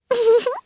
Hanus-Vox_Happy2_jp.wav